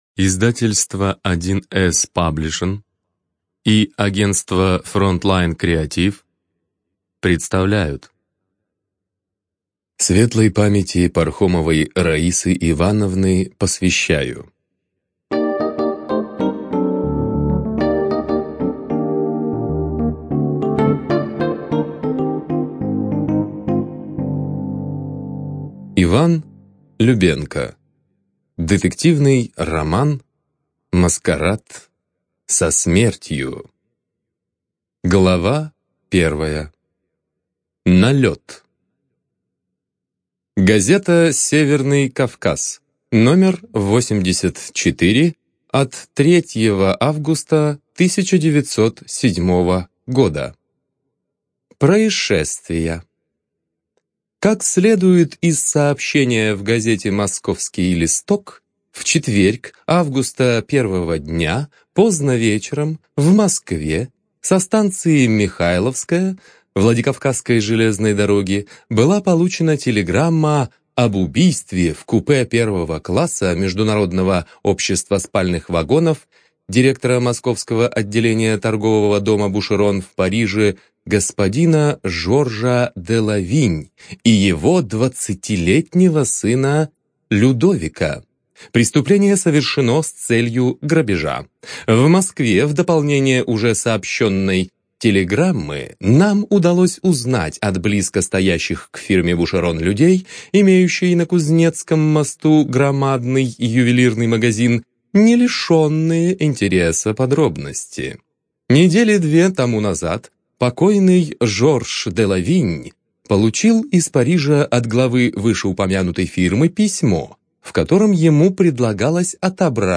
Студия звукозаписи1С-Паблишинг
Жанр: Исторический детектив